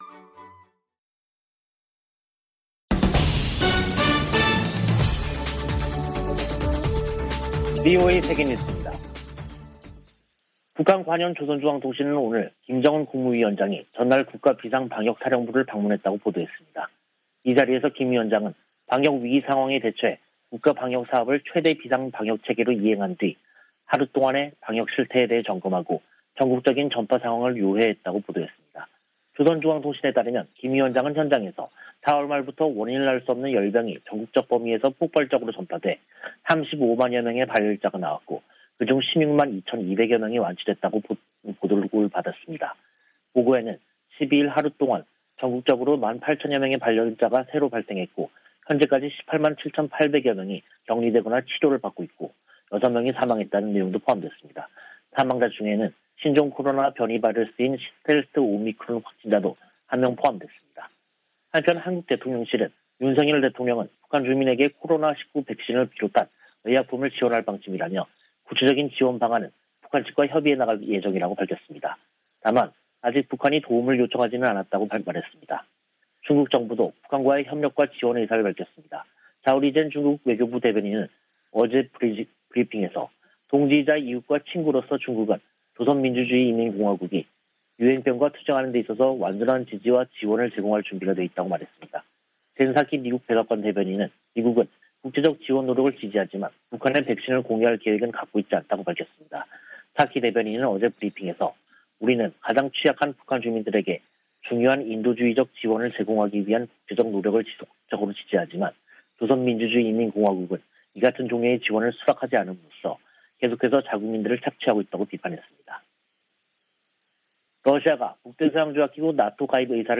VOA 한국어 간판 뉴스 프로그램 '뉴스 투데이', 2022년 5월 13일 2부 방송입니다. 백악관은 북한이 이달 중 핵실험 준비를 끝낼 것으로 분석했습니다.